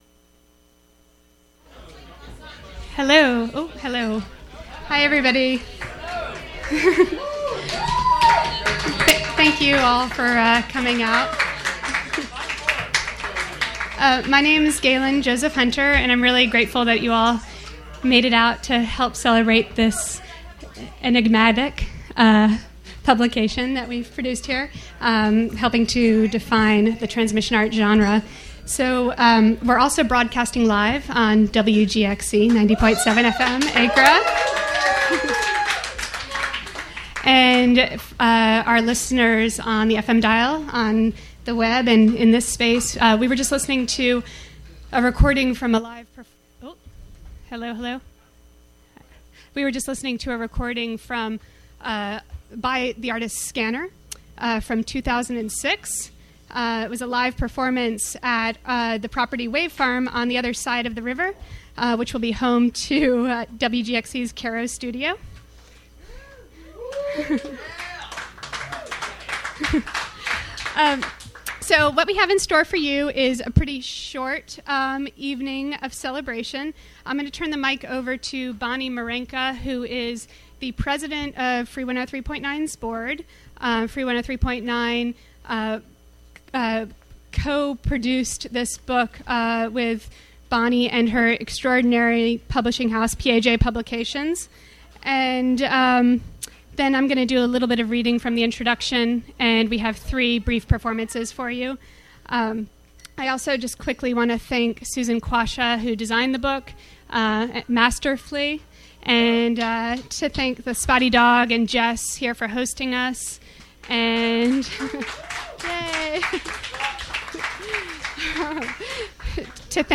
Introductory Remarks at Transmission Arts Book Launch (Audio)
Book party for Transmission Arts: Artists & Airwaves: Jul 23, 2011: 6pm - 8pm